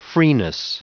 Prononciation du mot freeness en anglais (fichier audio)
Prononciation du mot : freeness